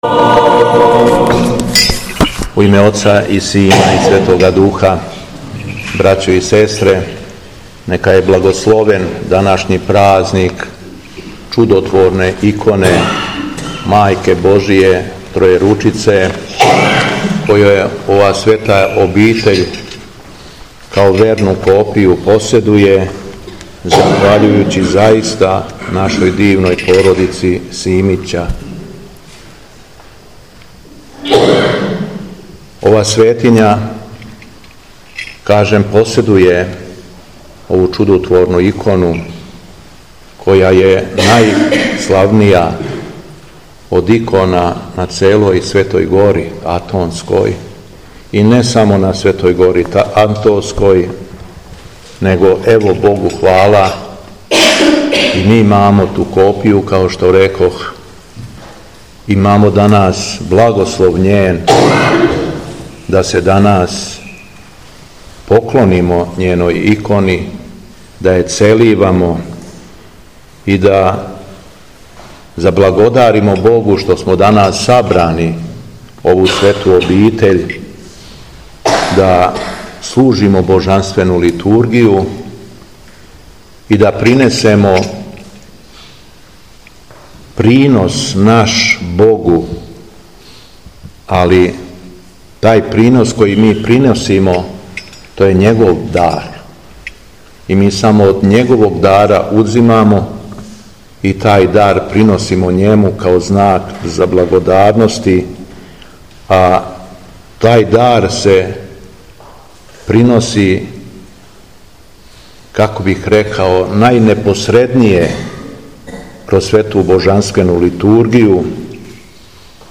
У петак 25. јула 2025. године, када наша Света Црква прославља Чудотворну икону Пресвете Богородице Тројеручице, његово Високопреосвештенство Митрополит шумадијски Господин Јован, служио је Свету Архијерејску Литургију у манастиру Каленић, у ком се чува чудотворна копија ове иконе.
Беседа Његовог Високопреосвештенства Митрополита шумадијског г. Јована